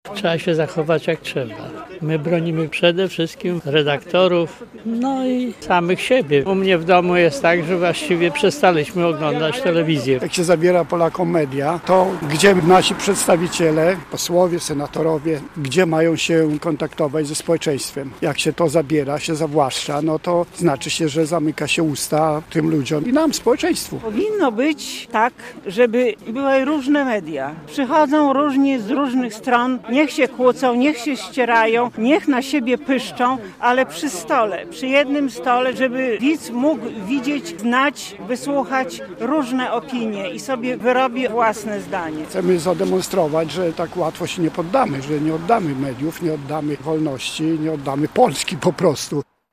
Protest przed siedzibą TVP3 Białystok - relacja